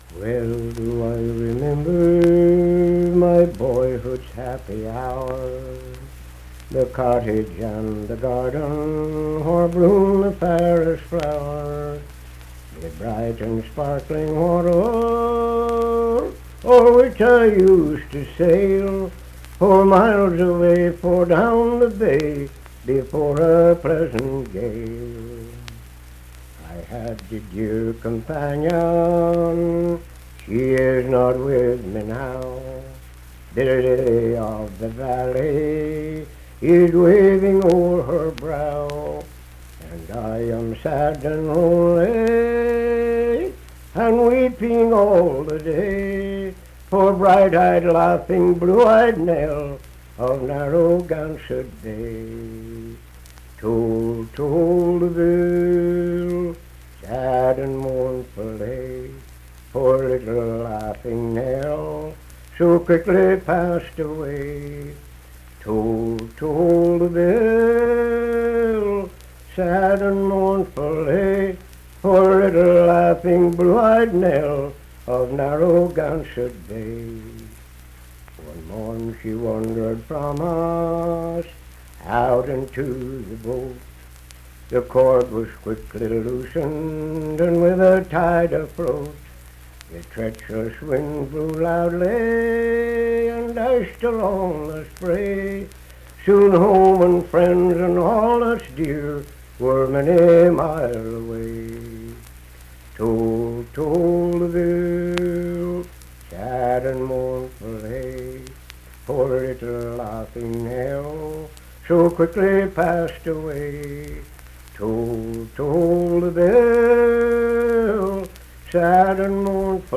Unaccompanied vocal music performance
Verse-refrain 4d(4) & Rd(4).
Voice (sung)